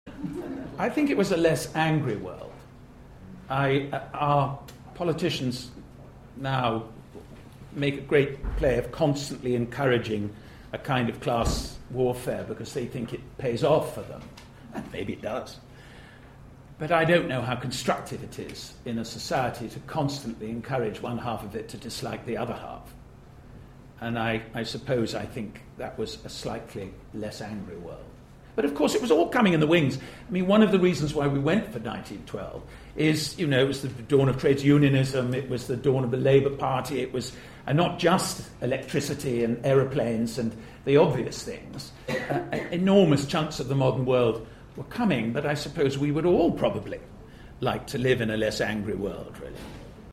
Then small round table interviews with many of the actors involved.